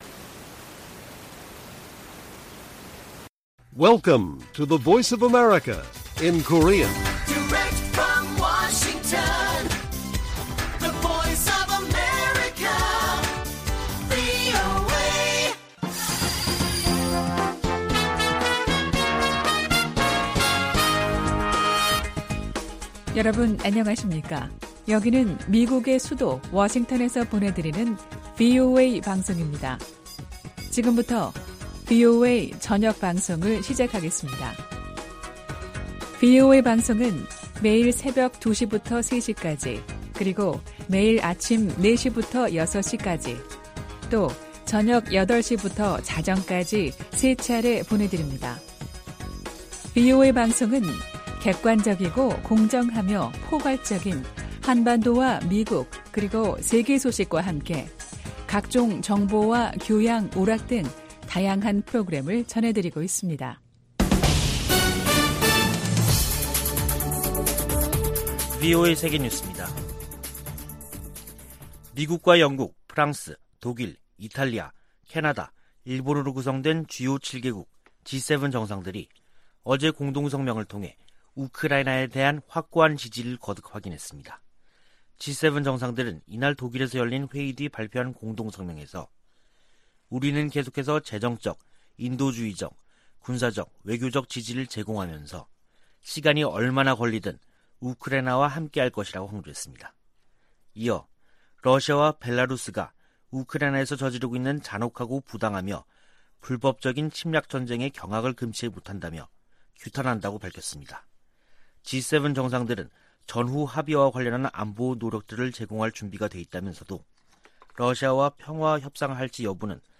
VOA 한국어 간판 뉴스 프로그램 '뉴스 투데이', 2022년 6월 28일 1부 방송입니다. 존 아퀼리노 미 인도태평양사령관이 미한 동맹을 역내 자유 수호의 핵심축이라고 말했습니다. 미 태평양함대사령관이 한일 국방장관 등을 만나 북한의 도발과 한반도 안보 상황을 논의했습니다. 유엔 산하 포괄적 핵실험금지 조약기구(CTBTO)가 북한에 핵실험 유예 약속 연장을 촉구했습니다.